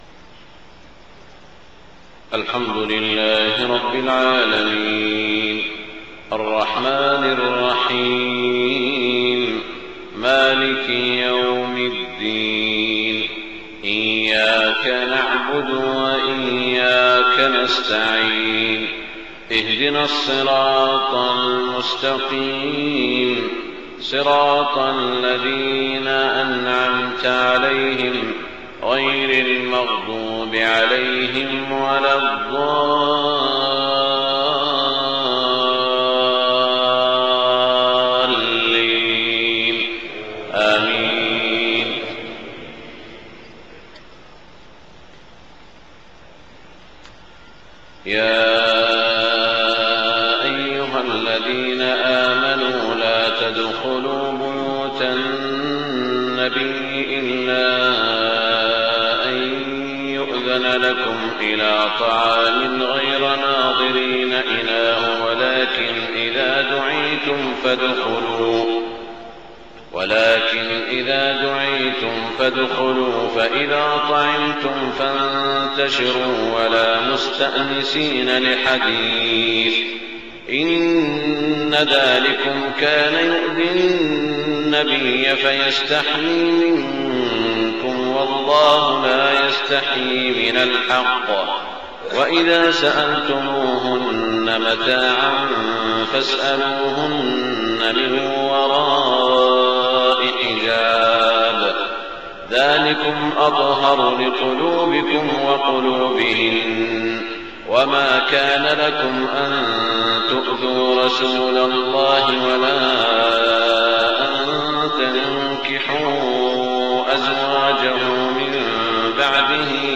صلاة الفجر 6-4-1428هـ من سورة الأحزاب > 1428 🕋 > الفروض - تلاوات الحرمين